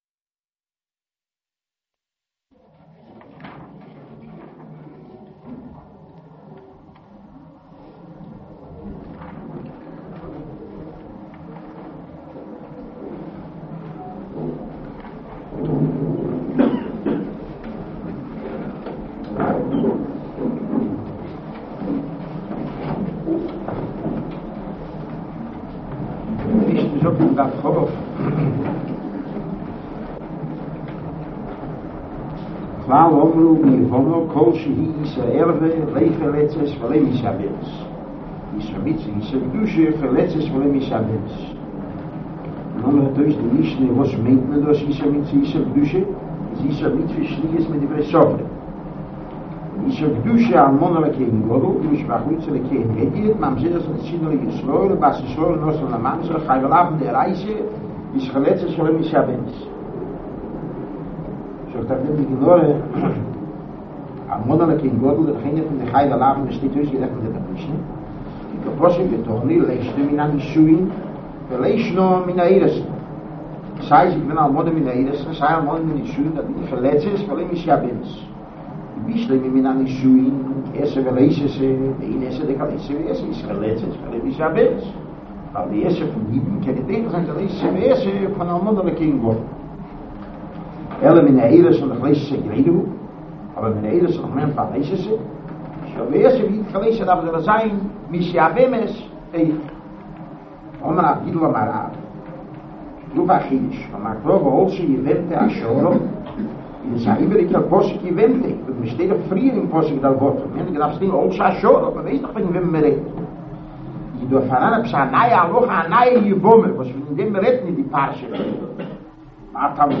giving shiur on Yivamos page 20.